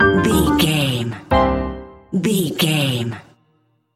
Uplifting
Aeolian/Minor
flute
oboe
strings
circus
goofy
comical
cheerful
perky
Light hearted
quirky